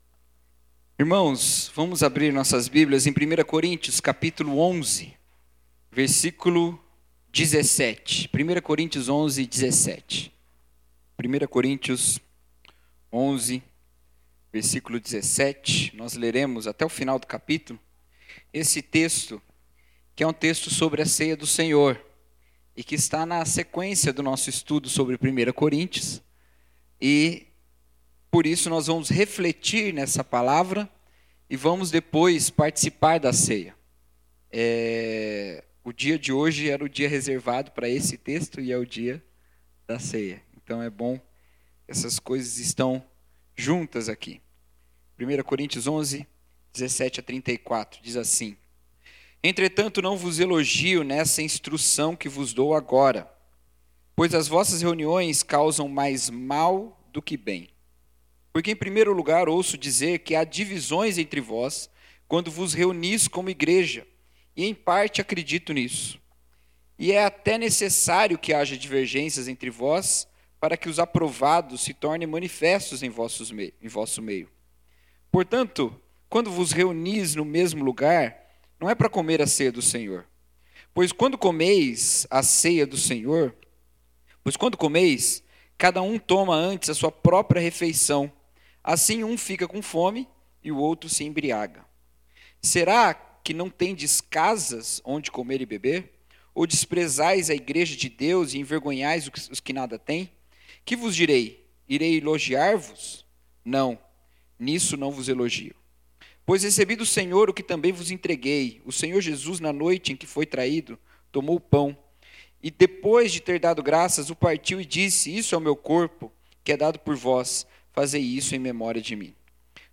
Mensagem: A Mesa da Comunhão